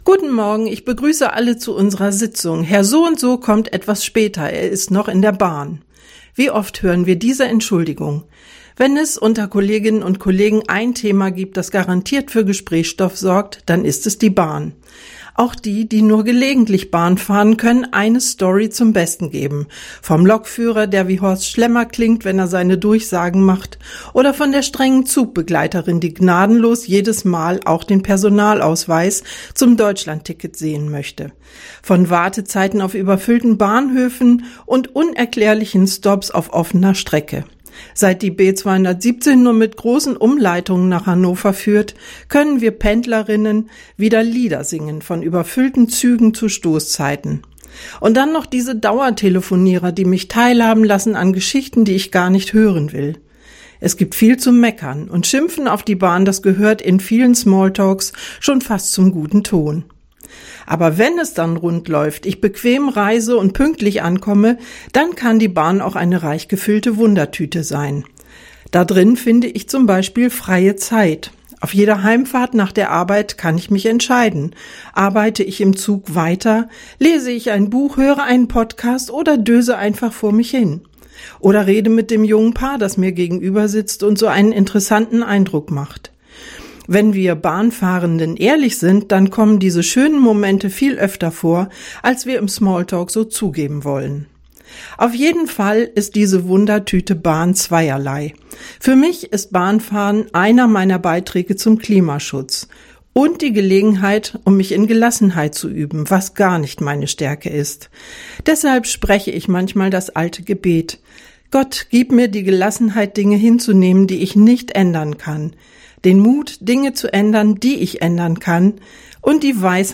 Radioandacht vom 28. September